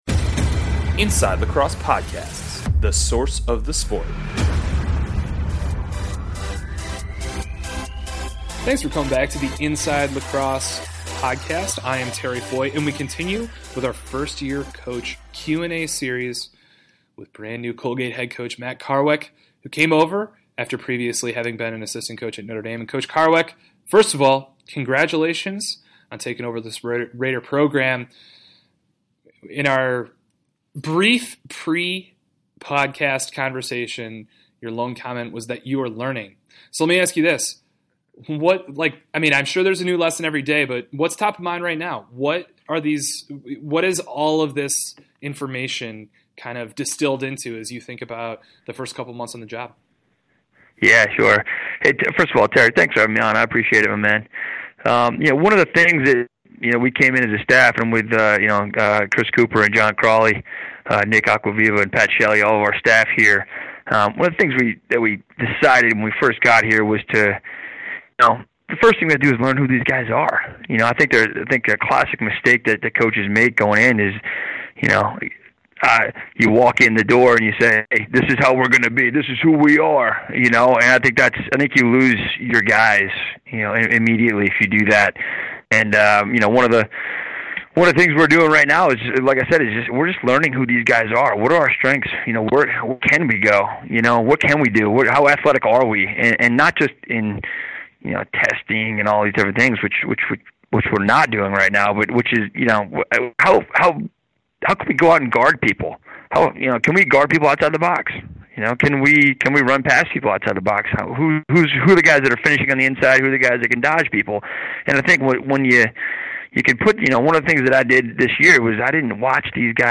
10/22 New Coach Interview